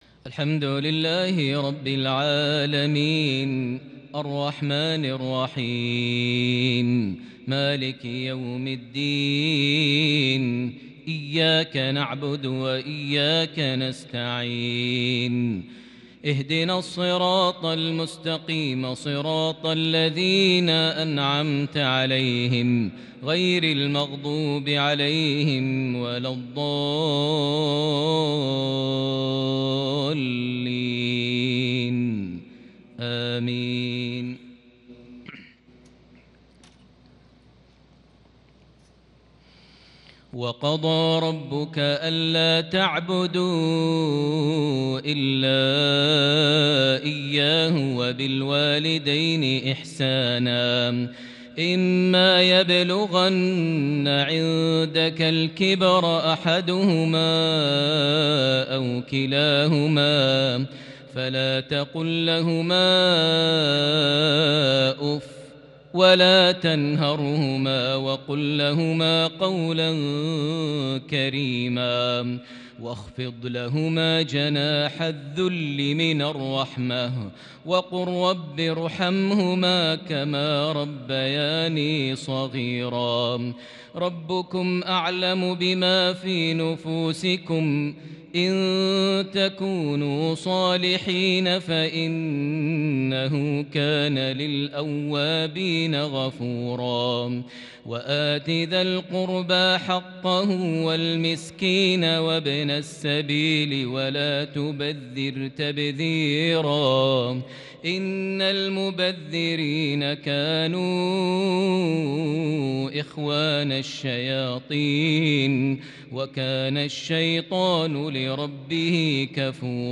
تلاوة مسترسلة من سورة الإسراء (23- 39)عشاء 19 ذو القعدة 1441هـ > 1441 هـ > الفروض - تلاوات ماهر المعيقلي